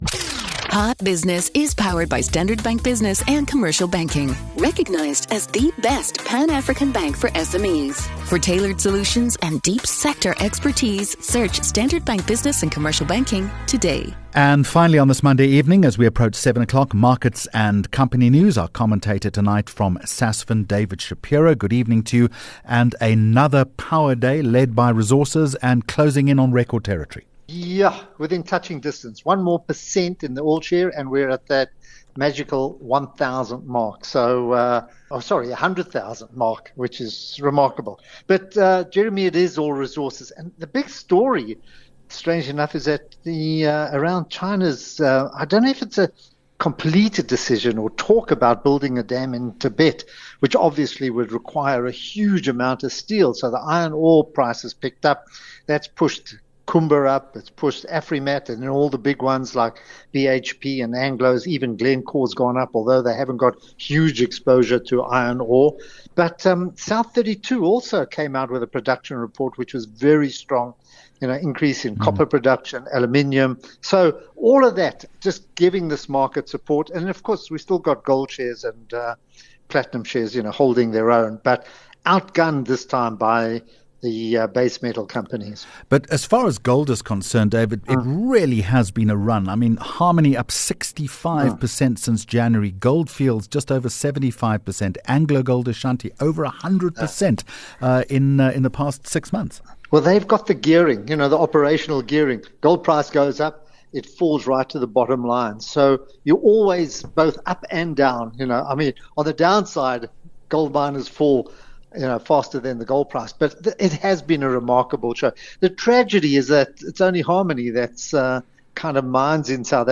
21 Jul Hot Business Interview